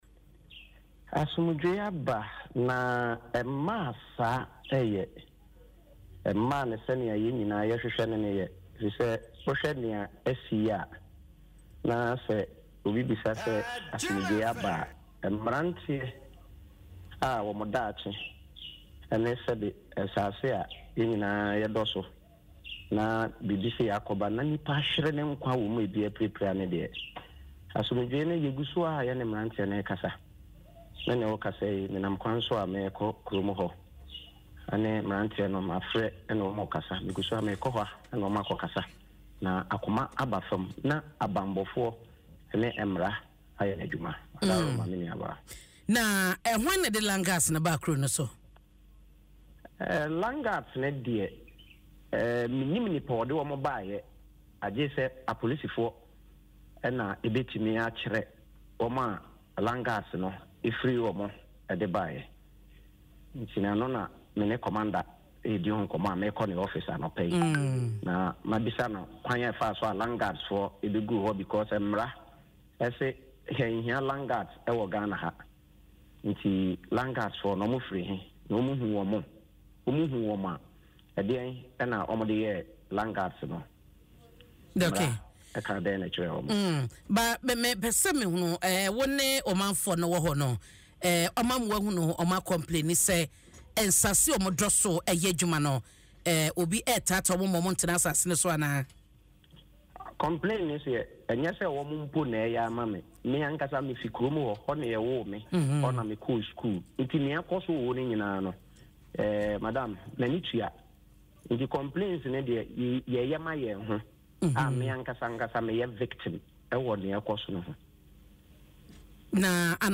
Speaking on Adom FM’s Dwaso Nsem program, Mr. Ofori revealed that this was not the first incident of violence in the area, and residents are worried about the ongoing clashes, which threaten their safety.